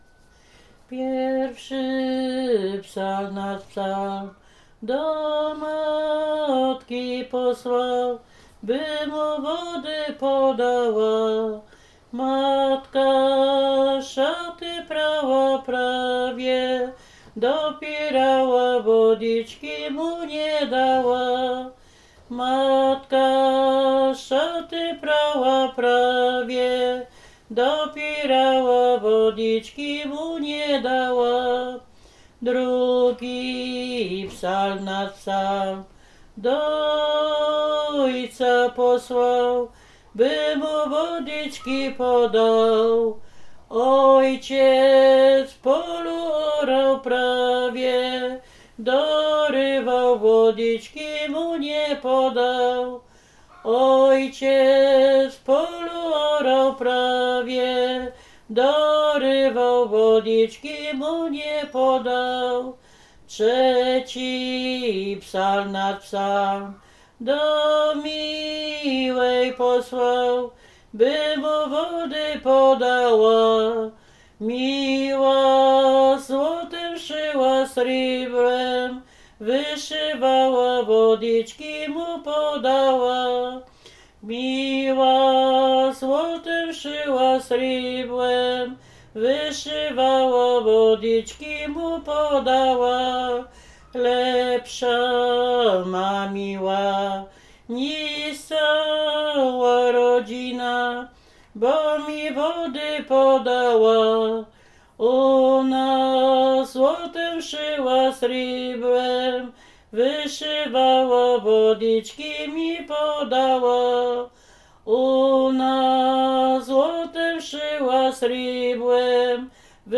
Liryka weselna
liryczne miłosne weselne